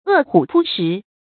餓虎撲食 注音： ㄜˋ ㄏㄨˇ ㄆㄨ ㄕㄧˊ 讀音讀法： 意思解釋： 饑餓的老虎撲向食物；比喻迅速地猛烈地向前沖去。